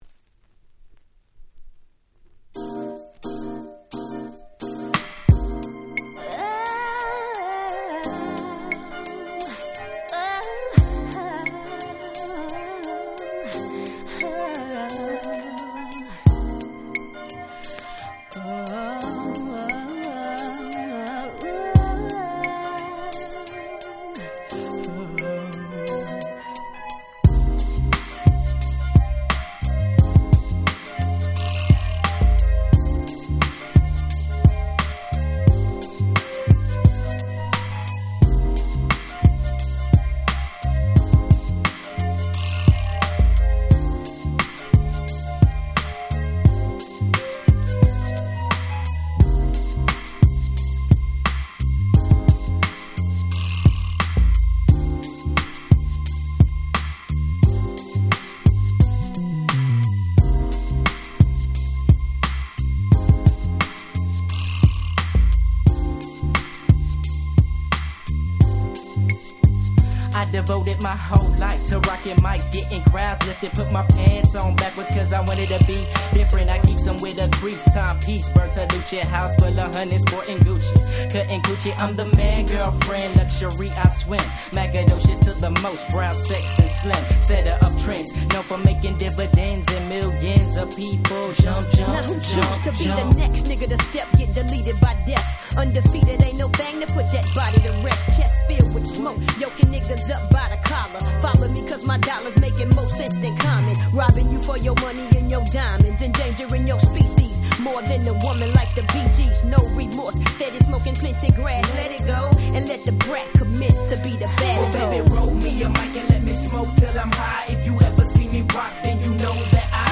96' Smash Hit Hip Hop !!